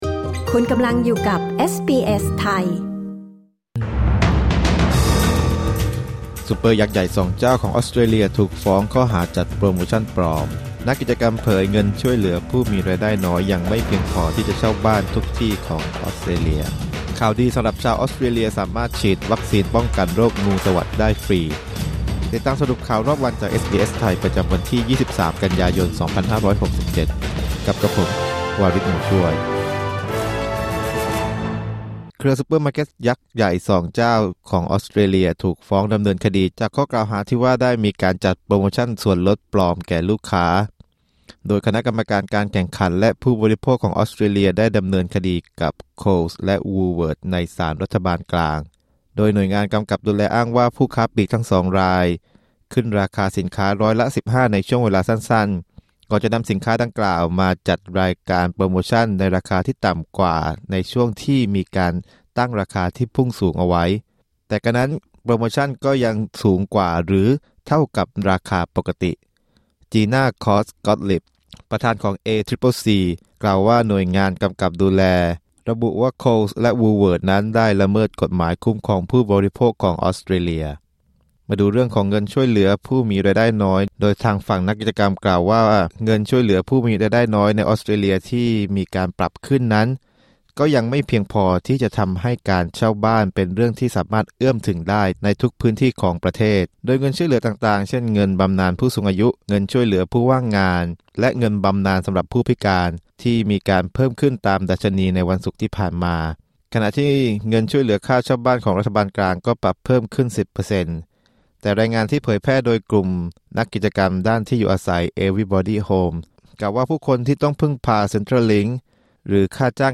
สรุปข่าวรอบวัน 23 กันยายน 2567